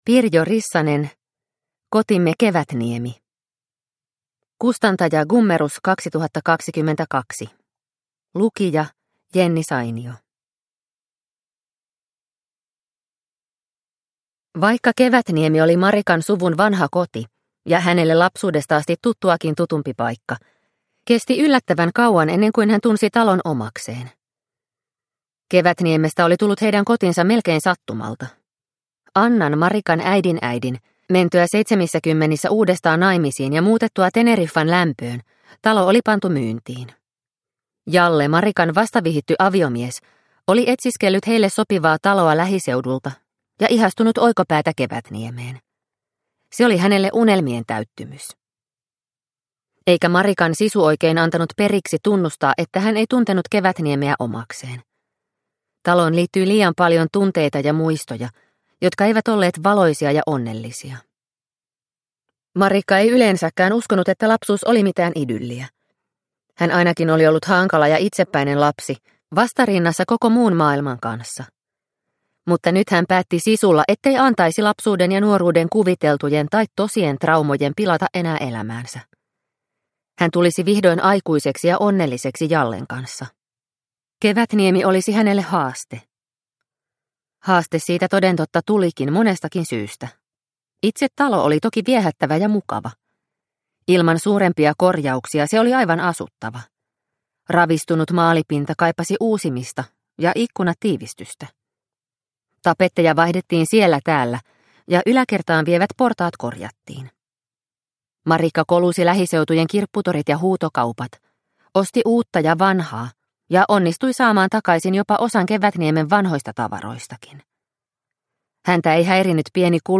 Kotimme Kevätniemi – Ljudbok – Laddas ner